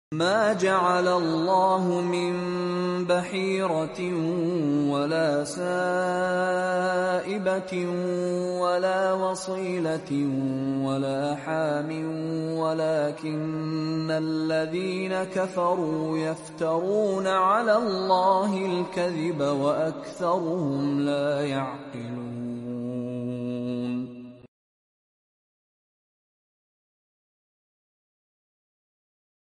quran recitation
Quran recitation Beautiful voice